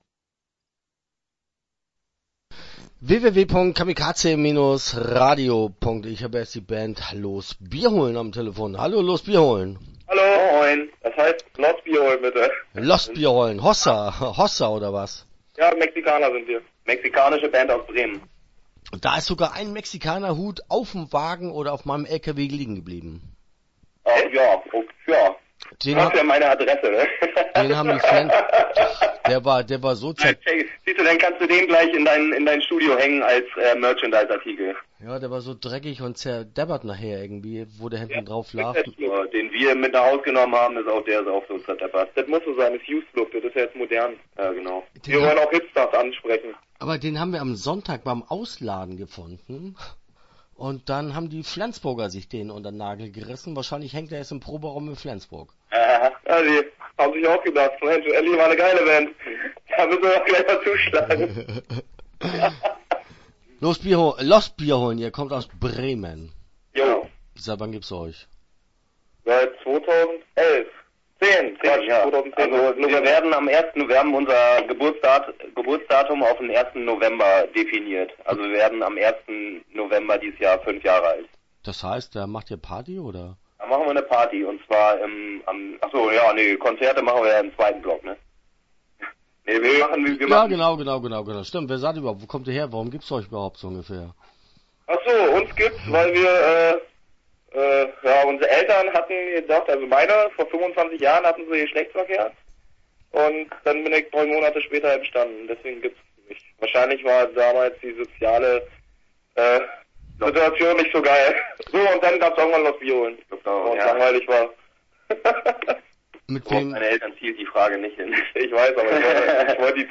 Los Bierholn - Interview Teil 1 (10:23)